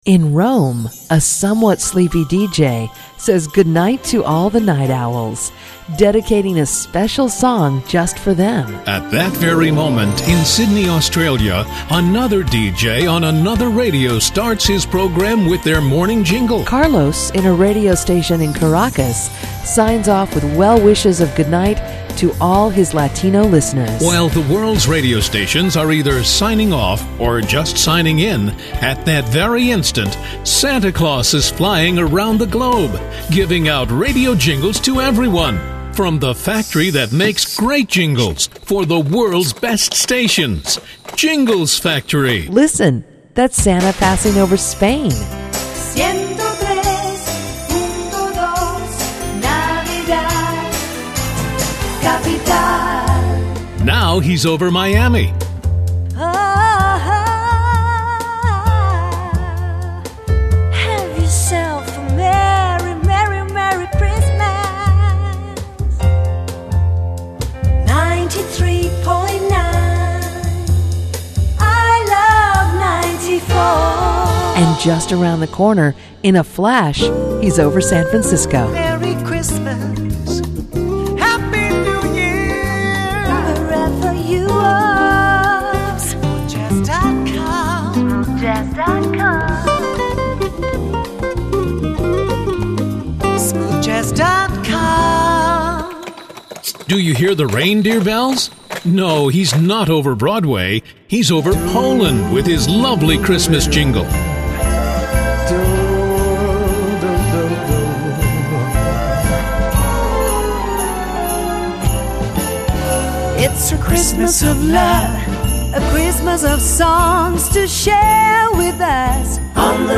Affordable Singing Jingles...Done Well !
DEMO REEL